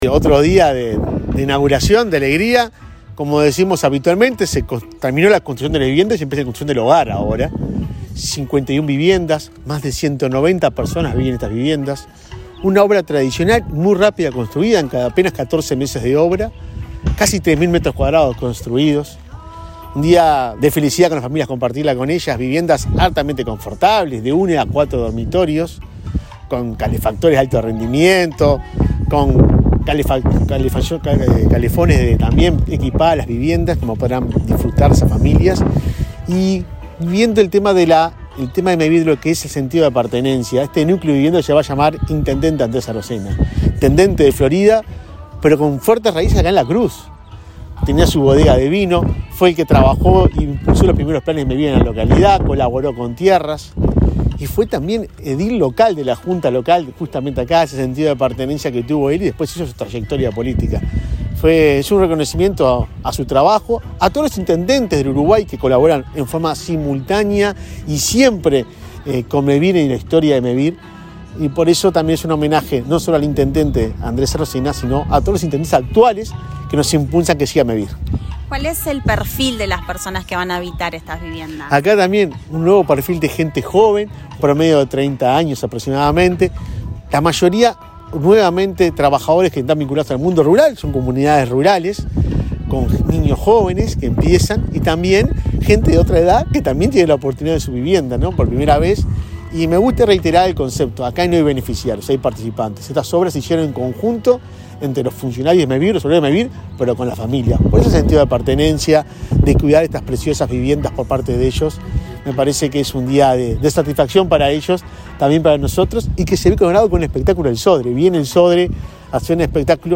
Declaraciones del presidente de Mevir, Juan Pablo Delgado
Declaraciones del presidente de Mevir, Juan Pablo Delgado 15/11/2022 Compartir Facebook X Copiar enlace WhatsApp LinkedIn Este martes 15, el presidente de Mevir, Juan Pablo Delgado, dialogó con Comunicación Presidencial, antes de participar en la inauguración de 51 viviendas en la localidad de La Cruz, departamento de Florida.